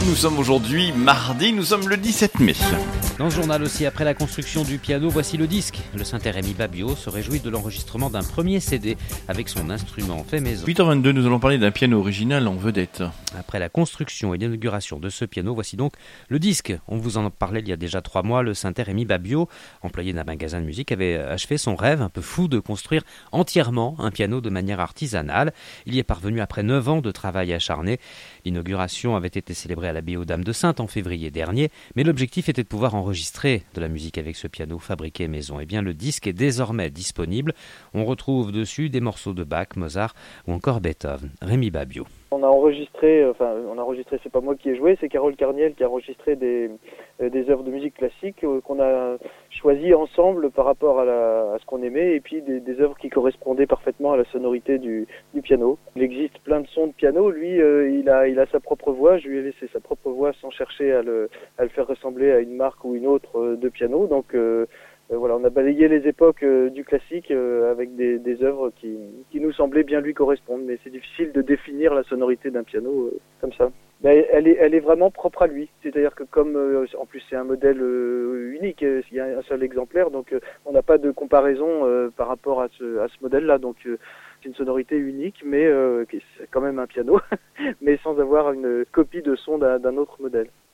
Le 17 Mai 2016, Demoiselle FM a fait la promotion du CD dans les journaux d'informations du